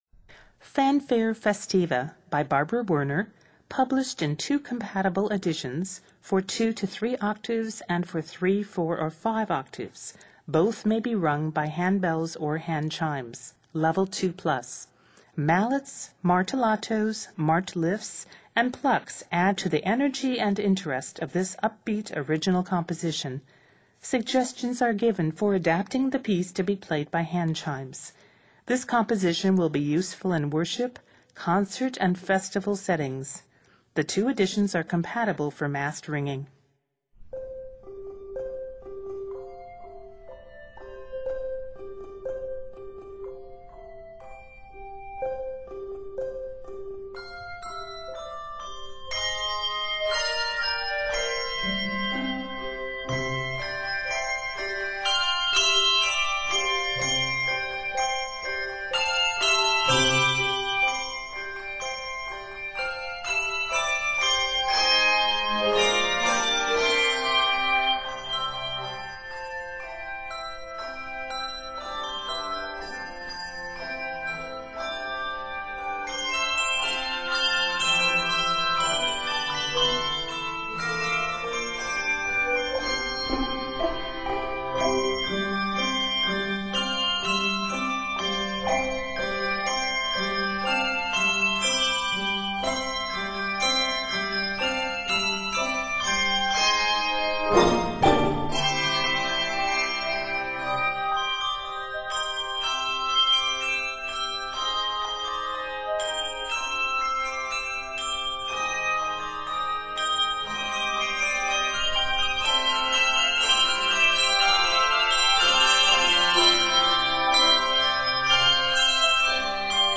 Written in C Major, measures total 56.